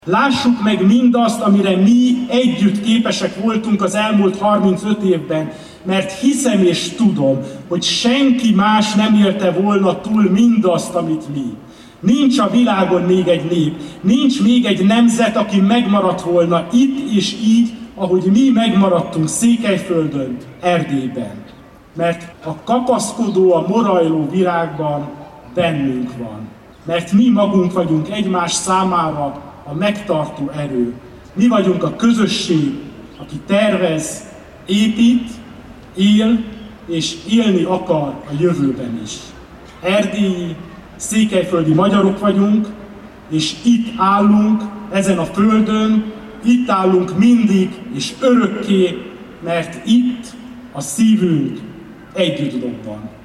Egymásba kell kapaszkodni és egymásból kell erőt meríteni, ezt üzenték március 15-i szónokok Sepsiszentgyörgyön.
Antal Árpád polgármester azzal indította beszédét, hogy „egyszer, egy március 15-ei ünnepségen jó volna arról beszélni, hogy milyen gondtalan a múlt.